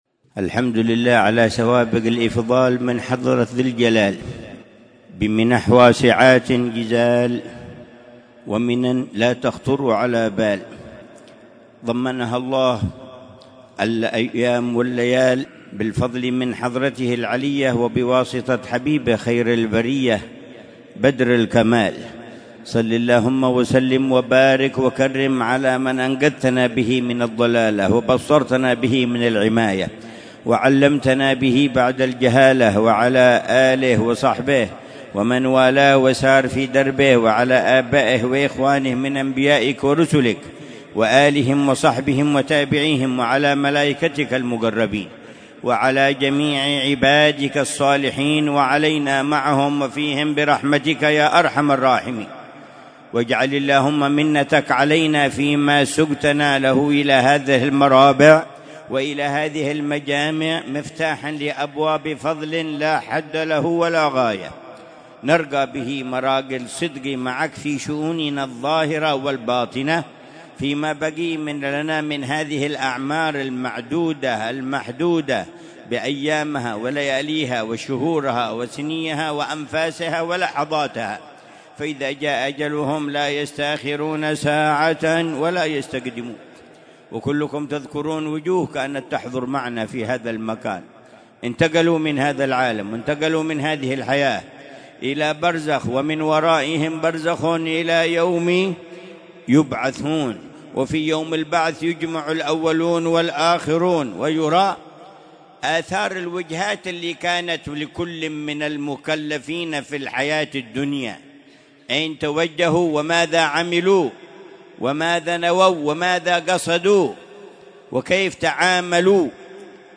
مذاكرة العلامة الحبيب عمر بن محمد بن حفيظ في مجلس الذكر والتذكير في الزيارة السنوية للشريفة الصالحة سلمى بنت عبد الله بن صالح بن الشيخ أبي بكر بن سالم في منطقة الخون، بوادي حضرموت، ضحى الخميس 9 رجب